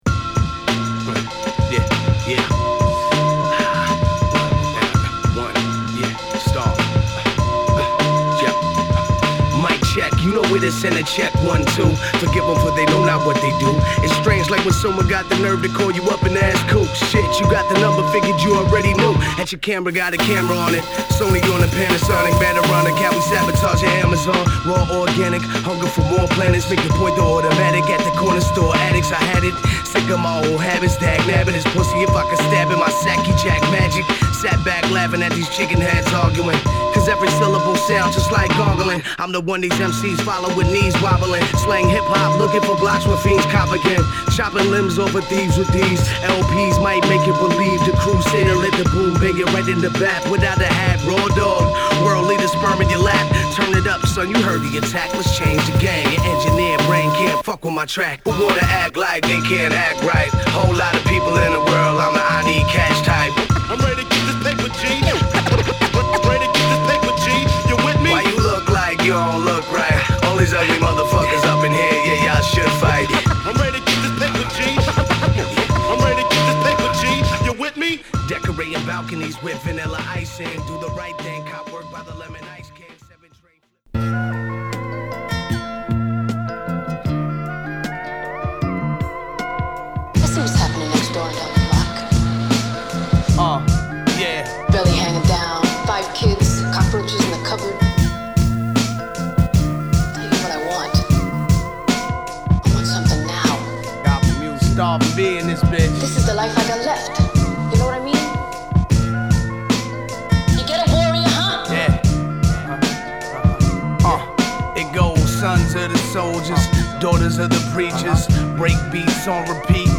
＊試聴はA3→B1→B2→B3です。